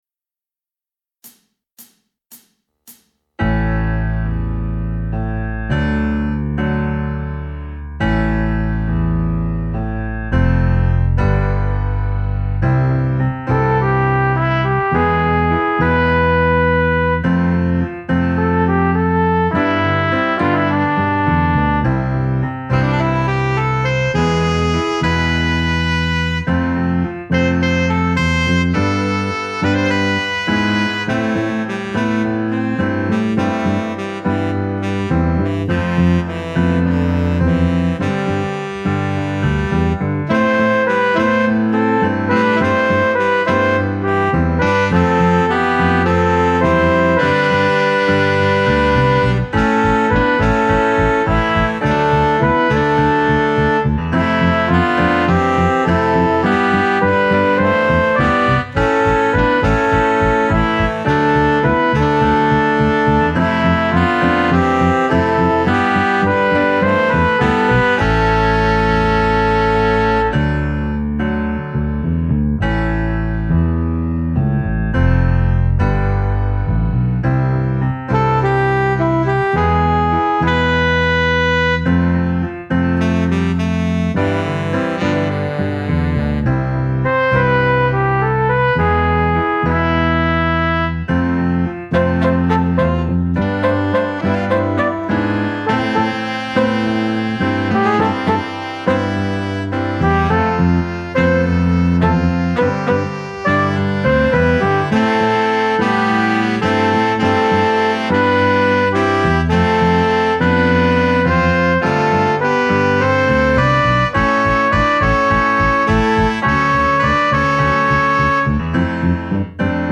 minus Drums